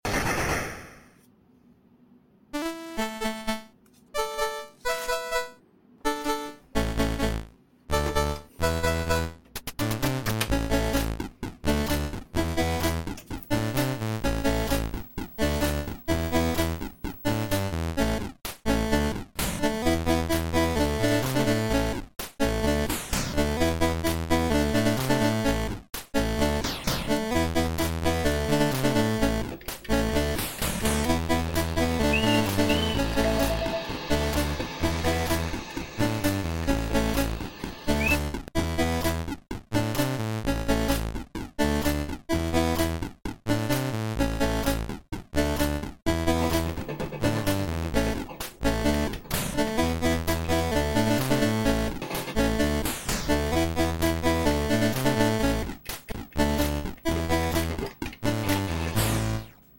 Retro Computer - California Games on the C64 - 1987!